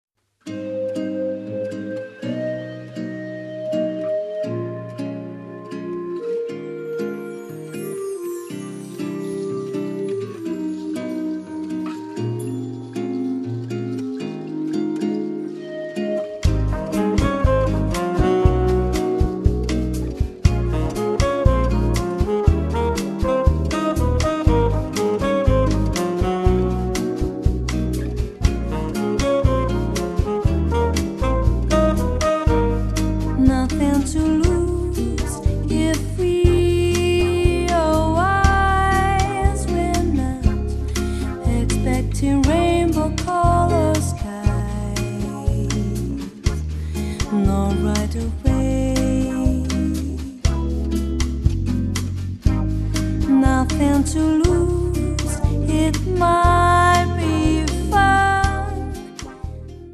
basso
batteria
percussioni
Il ritmo della bossa sottende tutti i brani
così limpida e dolce
meditativa e più brasiliana di tutte le tracce